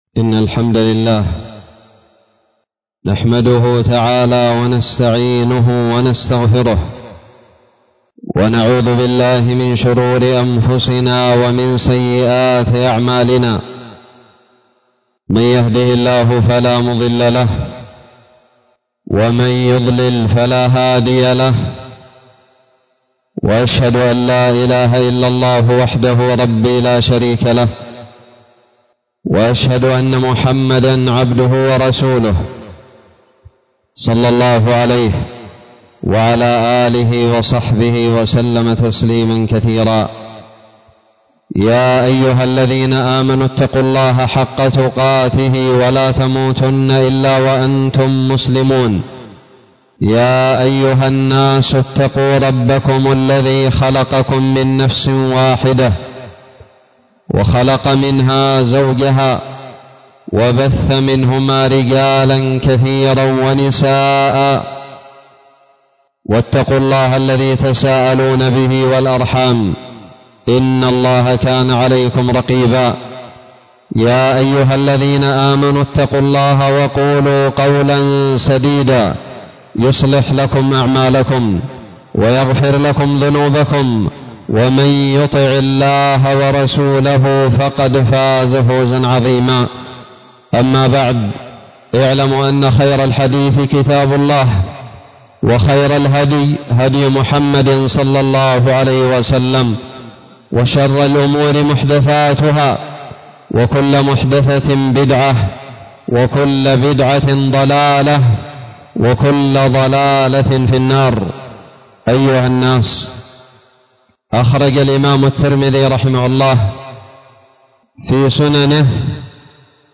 خطب الجمعة
ألقيت بدار الحديث السلفية للعلوم الشرعية بالضالع